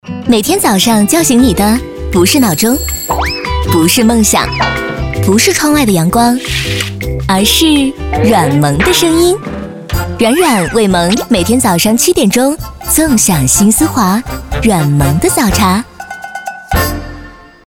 女国132_广告_片头片花_电台片花城市_活力.mp3